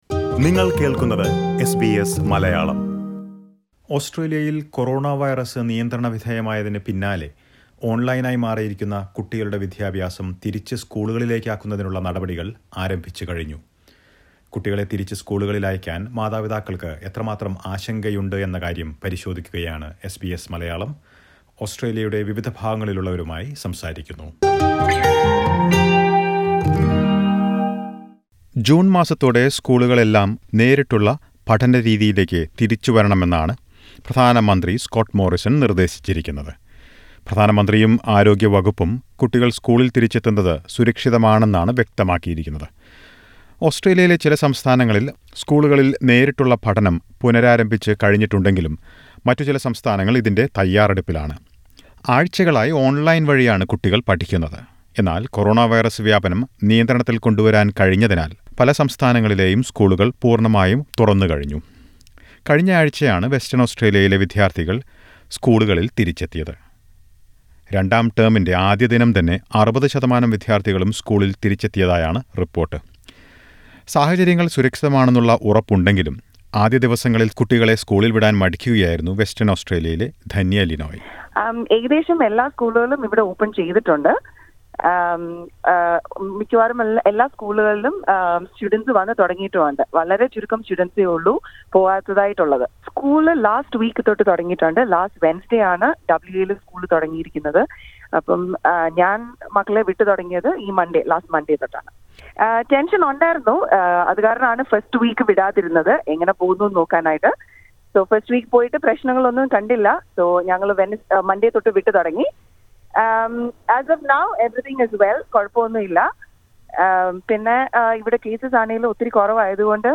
മാതാപിതാക്കൾക്ക് കുട്ടികളെ തിരിച്ചു നേരിട്ടുള്ള പഠനത്തിനായി സ്കൂളുകളിലേക്ക് അയക്കുന്നതിന് ആശങ്കയുണ്ടോ എന്ന കാര്യം എസ് ബി എസ് മലയാളം അന്വേഷിച്ചു. ചില മാതാപിതാക്കളുടെ പ്രതികരണം കേൾക്കാം മുകളിലെ പ്ലെയറിൽ നിന്ന്.